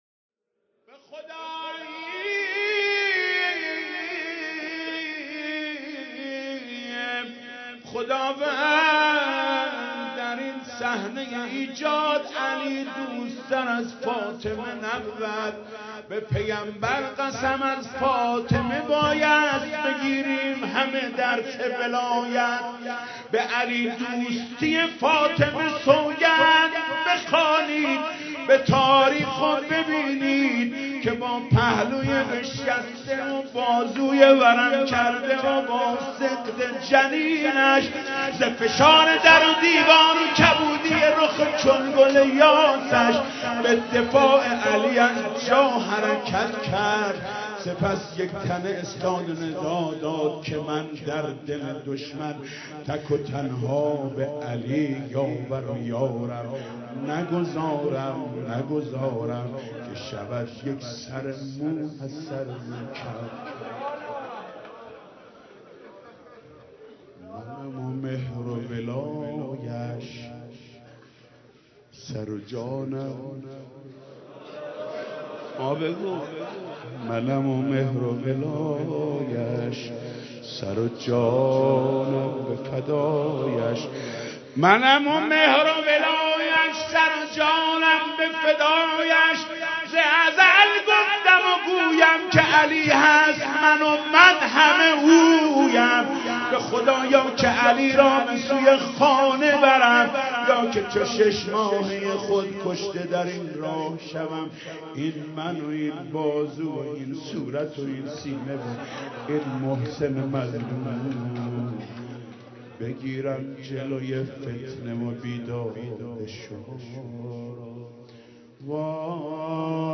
دانلود مداحی شهادت حضرت زهرا (س) ایام فاطمیه حاج محمود کریمی به خدا یی خداوند در این صحنه
به خدا یی خداوند در این صحنه (روضه)